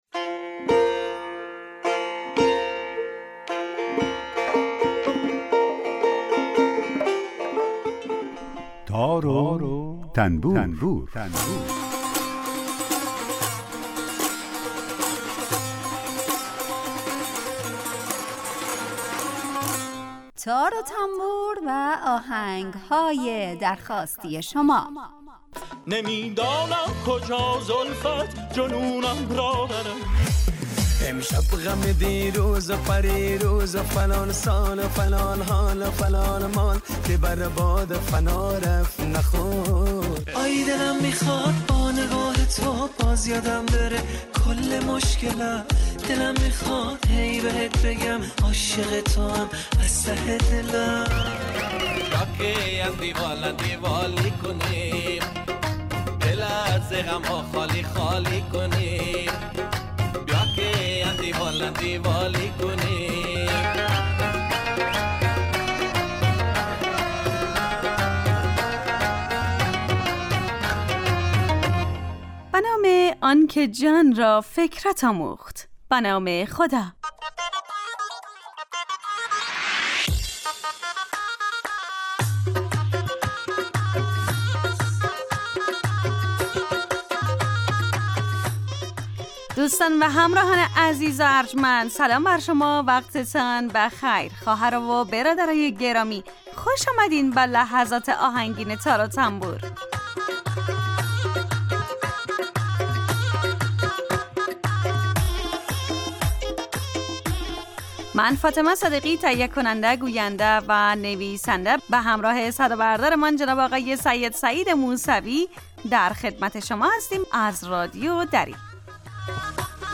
برنامه ای با آهنگ های درخواستی شنونده ها
یک قطعه بی کلام درباره همون ساز هم نشر میکنیم